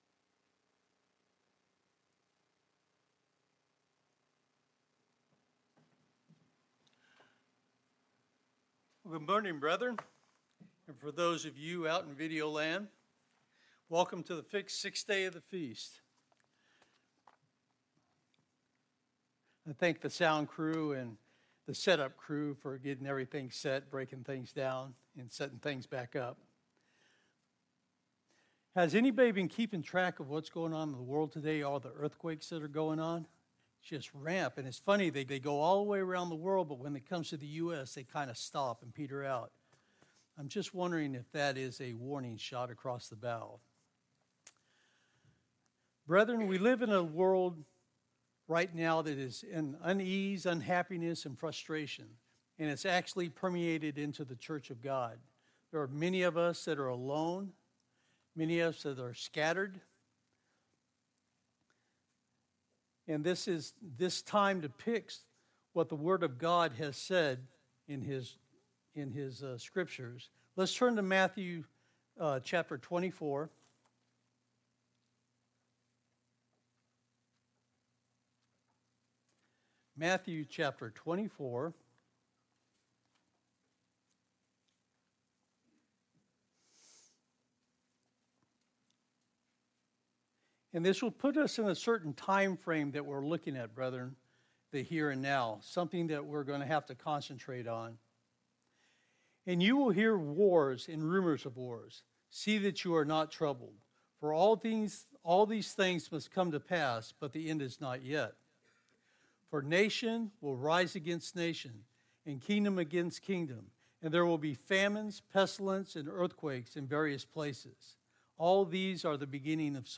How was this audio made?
From Location: "Kennewick, WA"